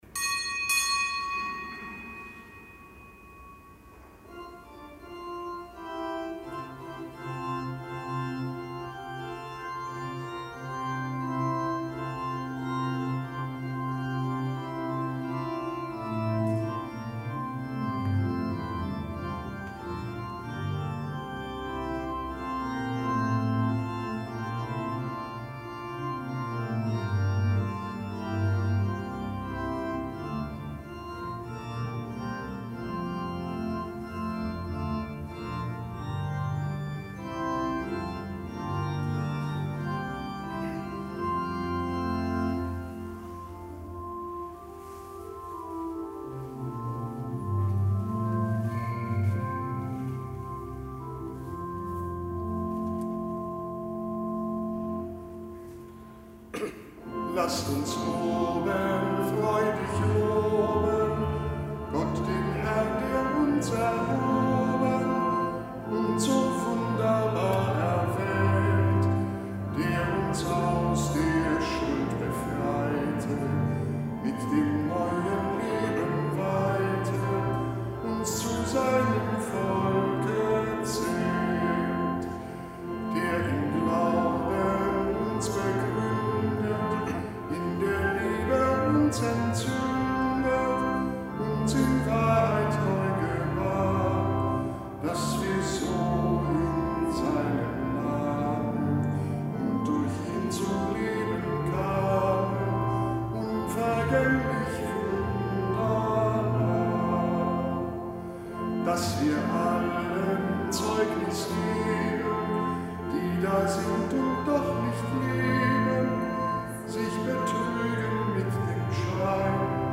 Kapitelsmesse aus dem Kölner Dom am Freitag der dritten Osterwoche. Nichtgebotener Gedenktag Heiliger Fidelis von Sigmaringen, Ordenspriester und Märtyrer.